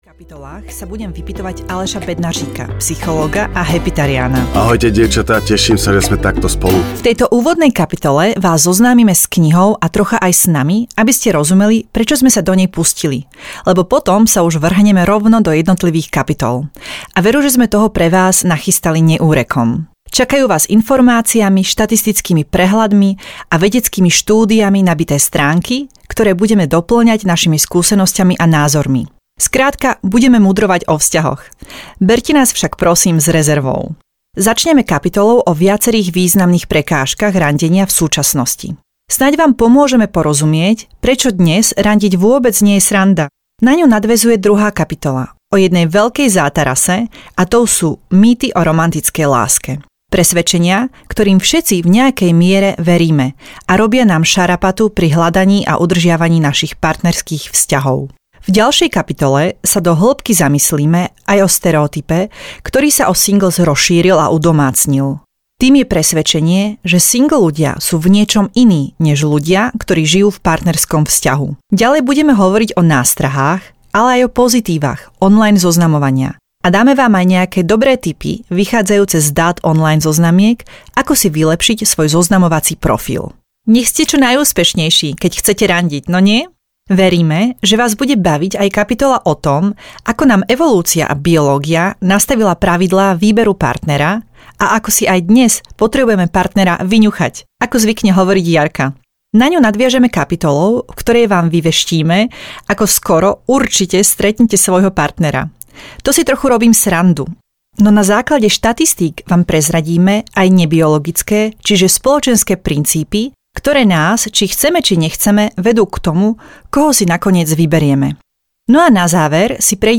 Labyrintom lásky audiokniha
Ukázka z knihy